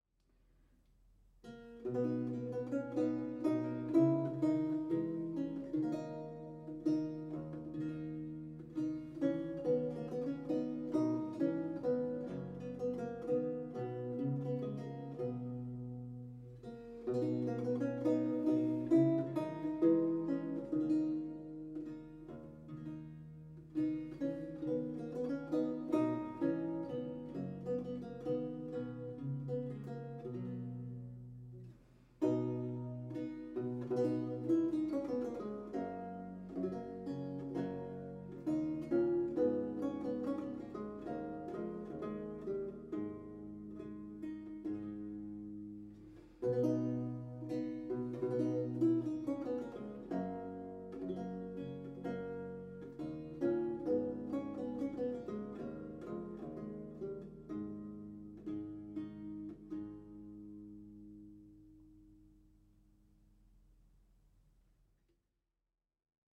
Audio recording of a lute piece